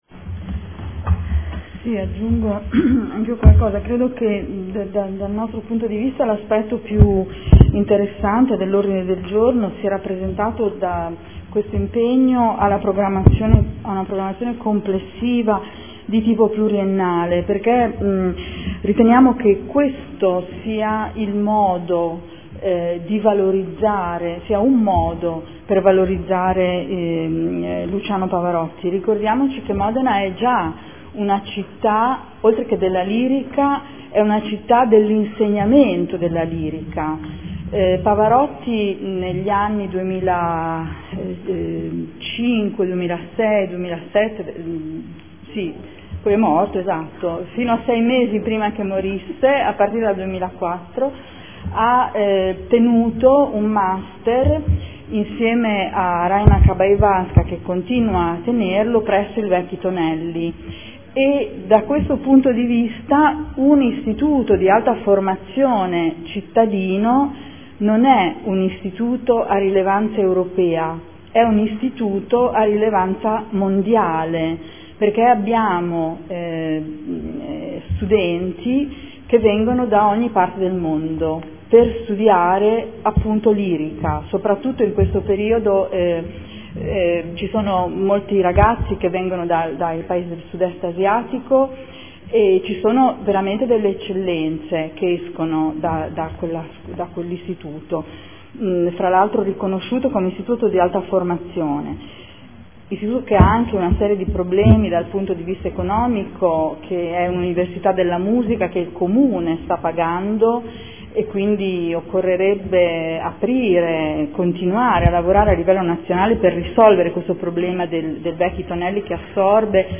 Seduta del 11/09/2014 Ordine del giorno unitario su Pavarotti.